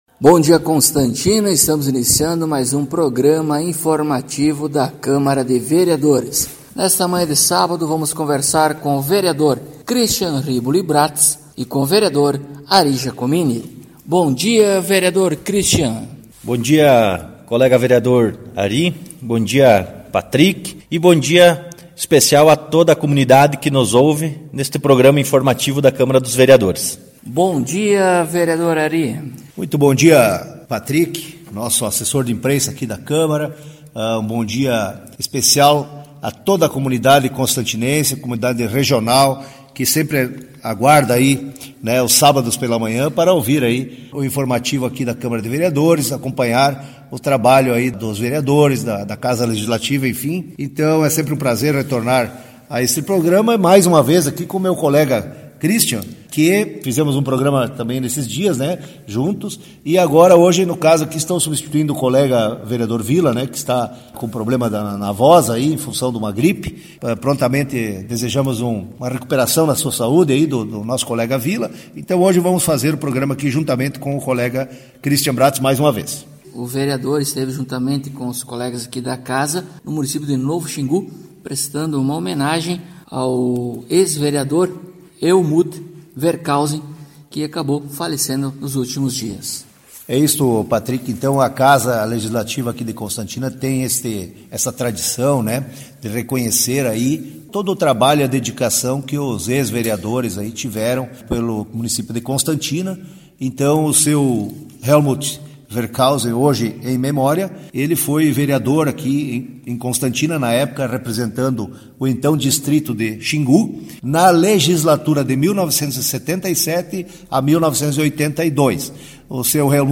Acompanhe o programa informativo da câmara de vereadores de Constantina com o Vereador Cristian Bratz e o Vereador Ari Giacomini.